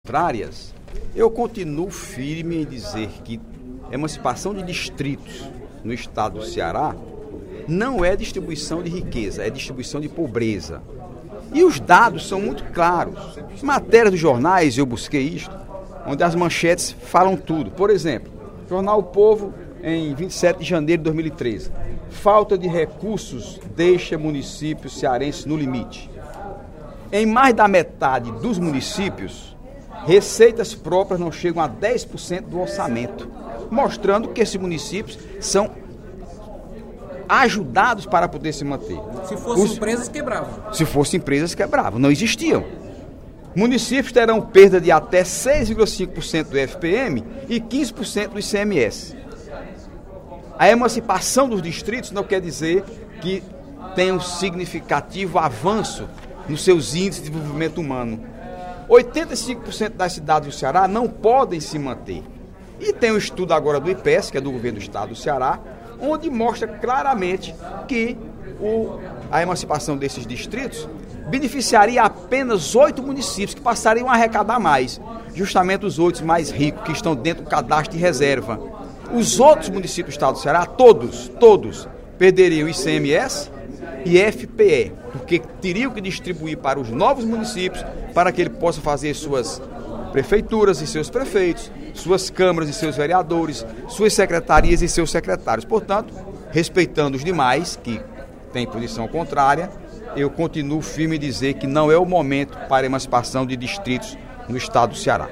No primeiro expediente da sessão plenária desta terça-feira (25/02), o deputado Heitor Férrer (PDT) afirmou que as emancipações de distritos cearenses geram prejuízos para os demais municípios do Estado.